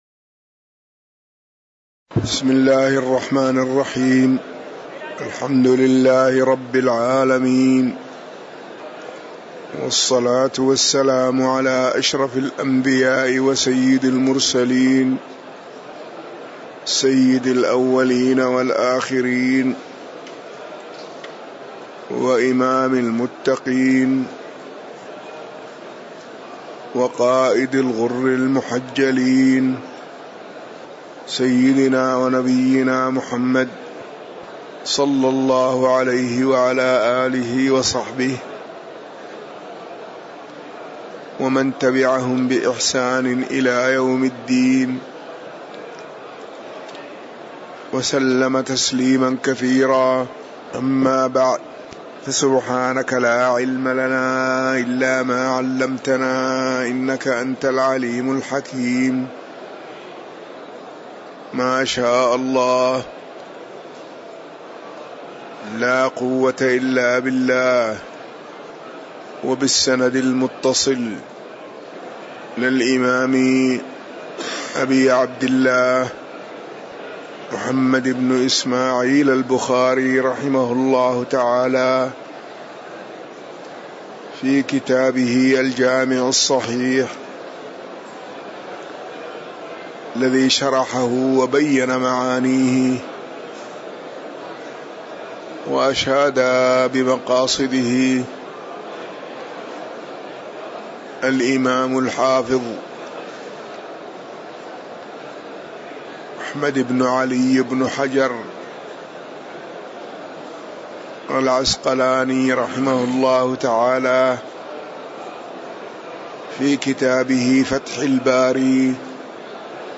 تاريخ النشر ٧ ربيع الأول ١٤٤١ هـ المكان: المسجد النبوي الشيخ